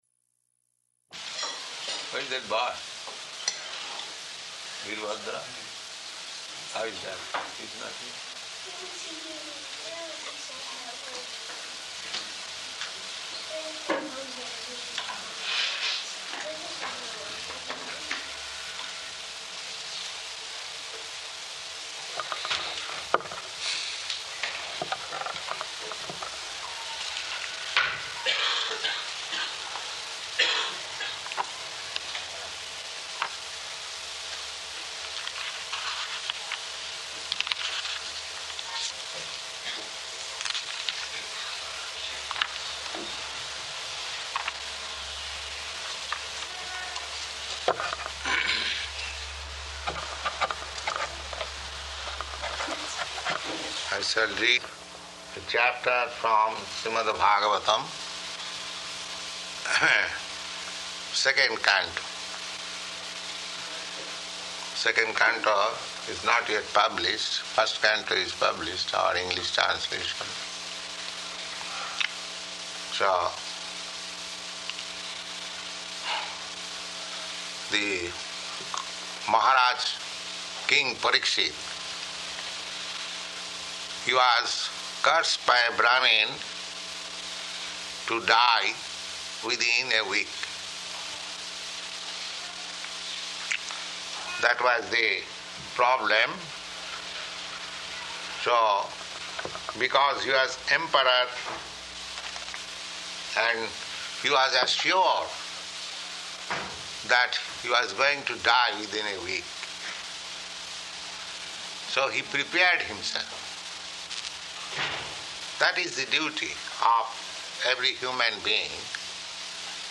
Śrīmad-Bhāgavatam Lecture